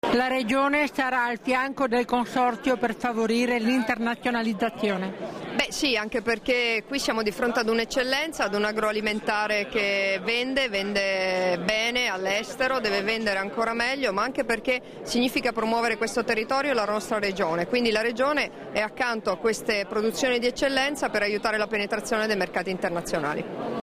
Ascolta le dichiarazioni di Debora Serracchiani (Formato MP3) [386KB]
rilasciate a margine dell'inaugurazione di "Aria di Festa", a San Daniele del Friuli il 28 giugno 2013